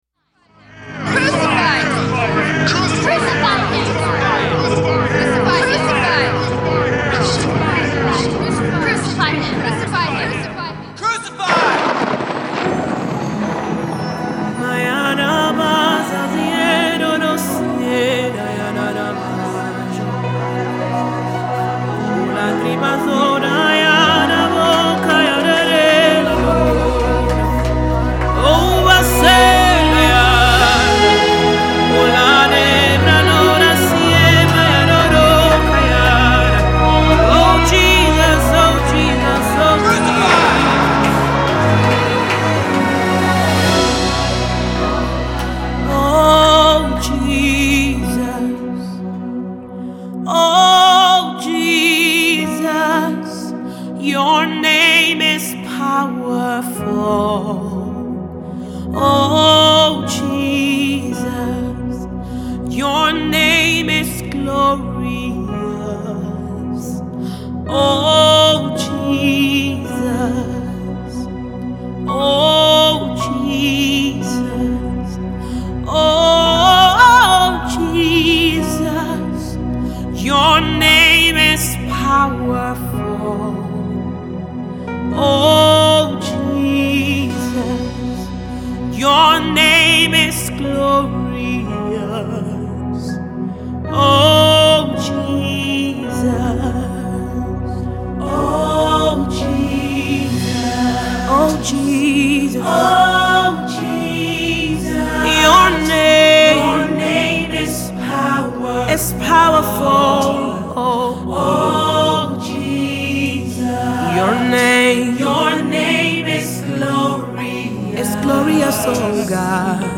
gospel
Still worship and highly addictive for the spirit.